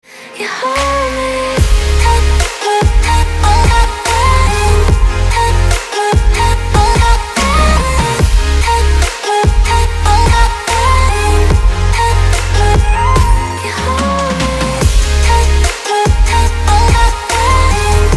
громкие
женский голос
озорные
Electropop
Классная поп/электропоп музыка